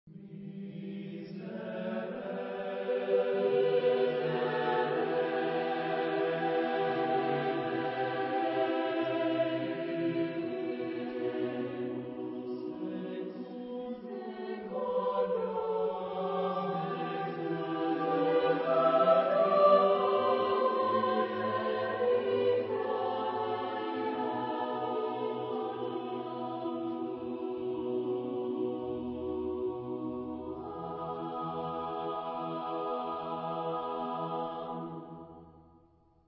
Genre-Style-Forme : Sacré ; Motet ; Psaume
Type de choeur : SATB  (4 voix mixtes )
Solistes : Bass (1)  (1 soliste(s))
Tonalité : mi mineur